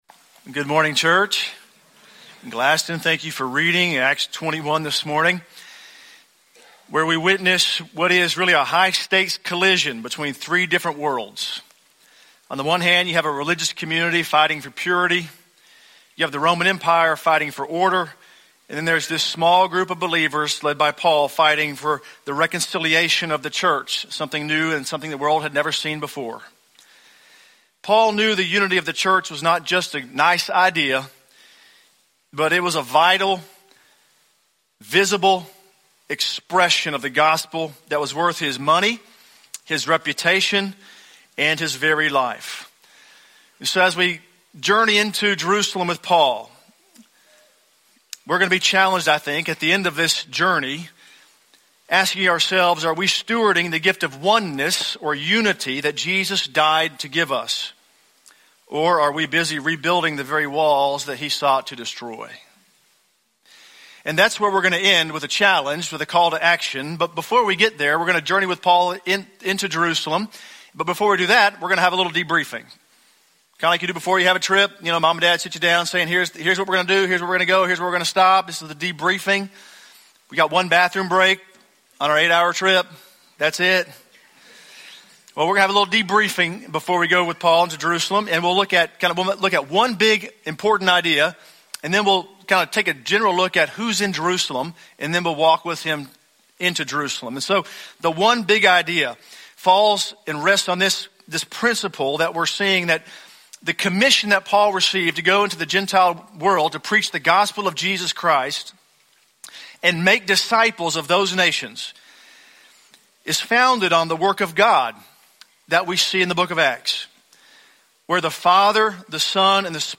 Sermon Archives
From Series: "English Sermons - 10:15"